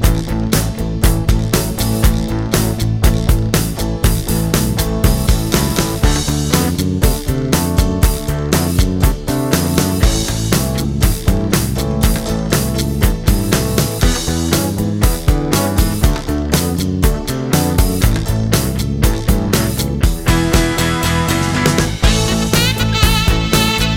One Semitone Down Pop (1970s) 2:53 Buy £1.50